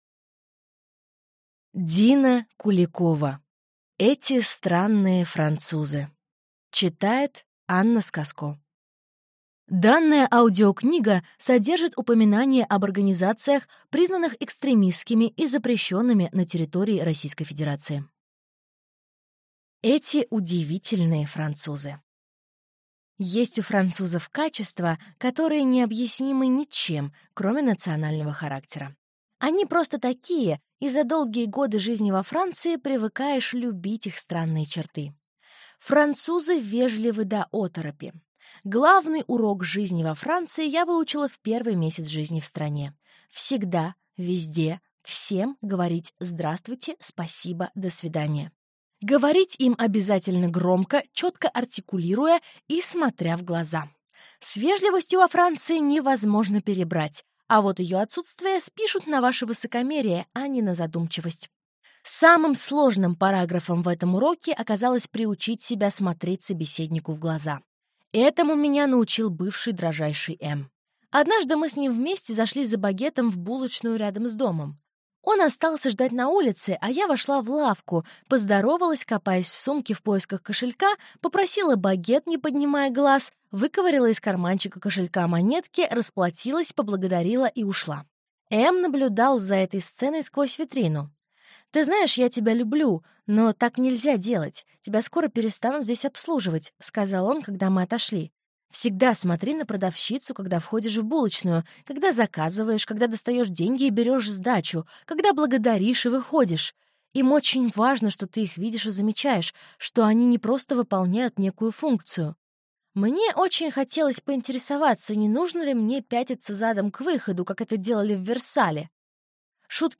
Аудиокнига Эти странные французы | Библиотека аудиокниг
Прослушать и бесплатно скачать фрагмент аудиокниги